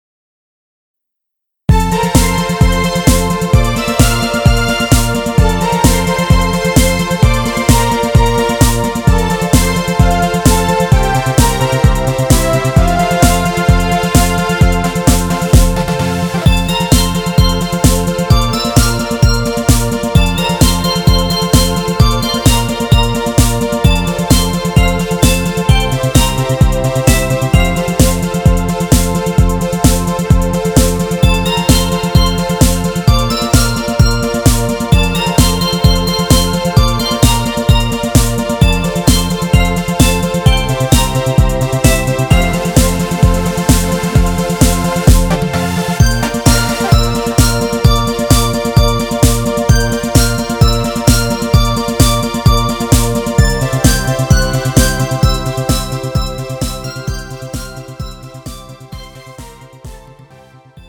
음정 F 키
장르 가요 구분 Pro MR